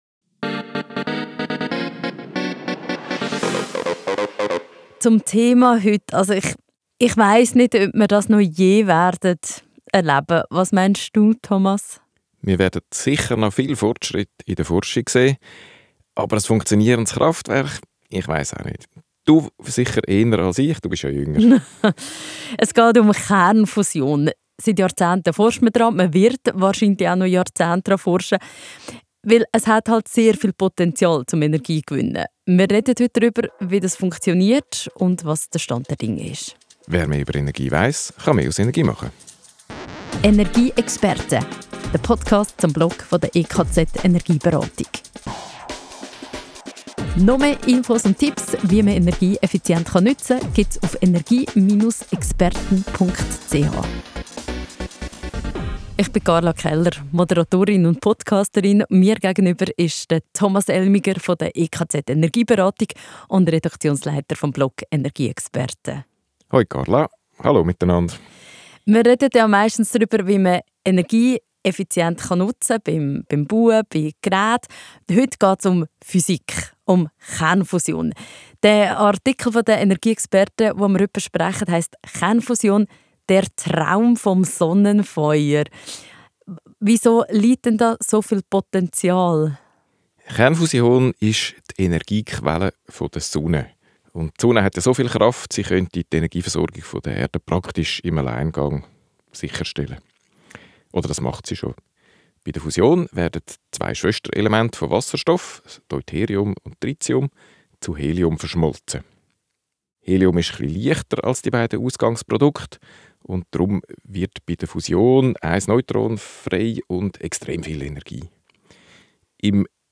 EP10-Kernfusion-Talk.mp3